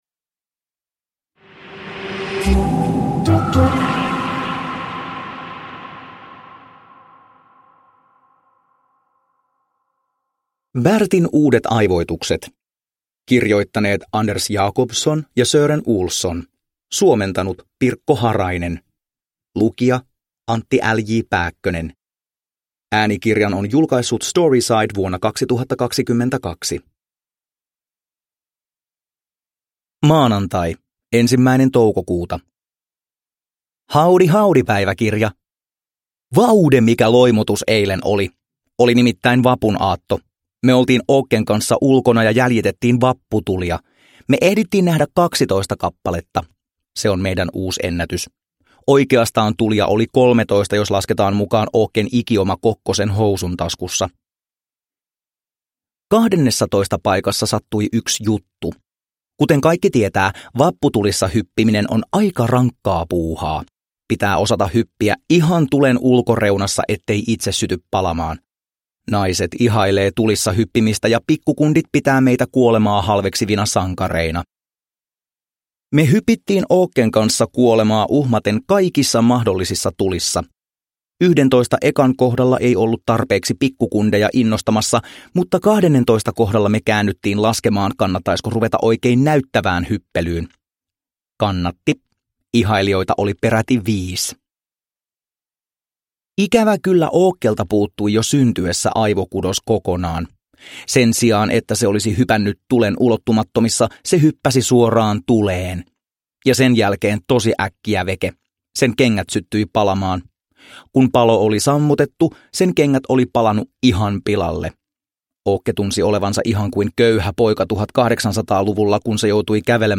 Bertin uudet aivoitukset – Ljudbok – Laddas ner
Uppläsare: Antti Pääkkönen